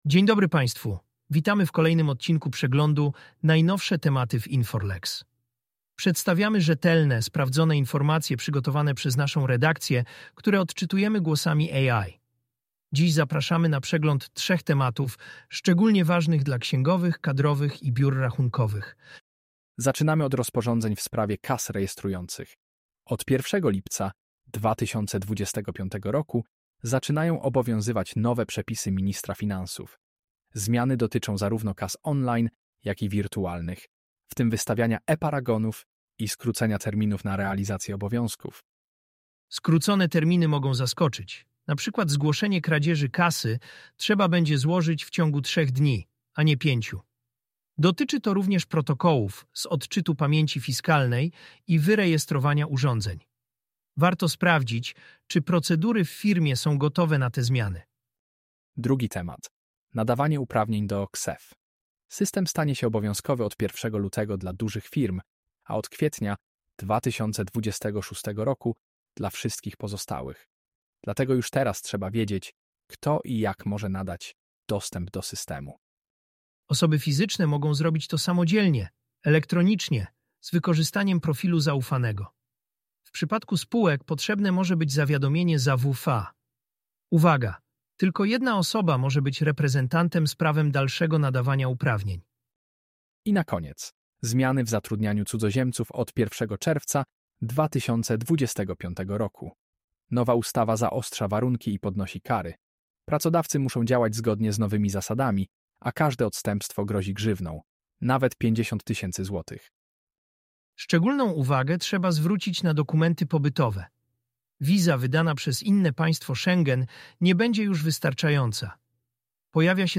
Głosy AI przedstawią najważniejsze tematy opracowane przez naszych ekspertów – w innowacyjnym formacie audio.